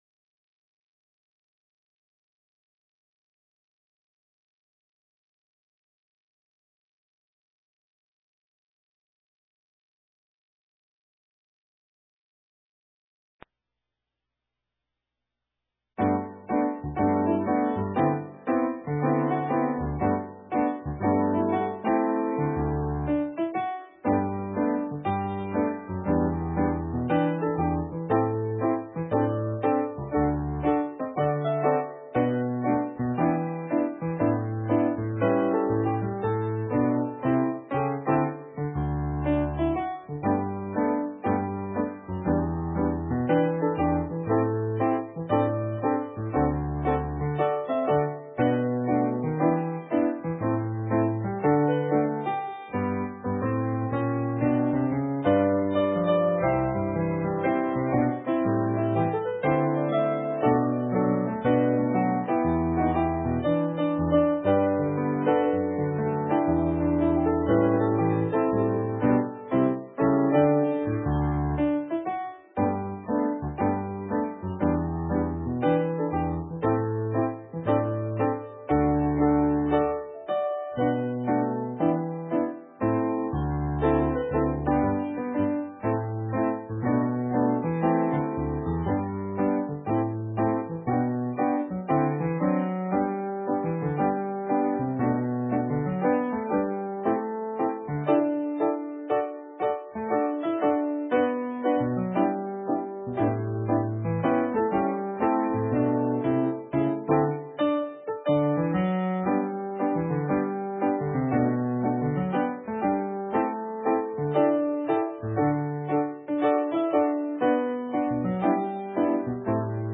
Sermon:The Frugalhorn's story - St. Matthews United Methodist Church